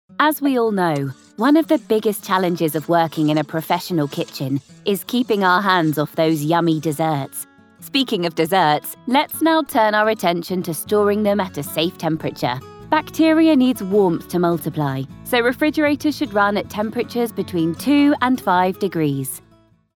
Natural, Playful, Versatile, Friendly, Warm
E-learning